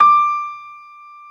55p-pno32-D5.wav